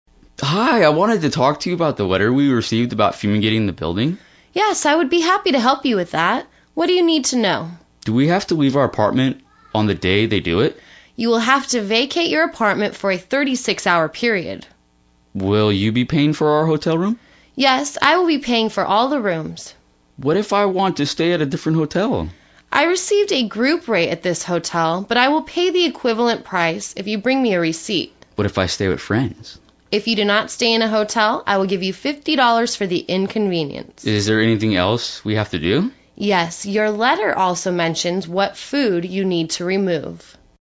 地道英语对话：Fumigation and Relocation for a Night(1) 听力文件下载—在线英语听力室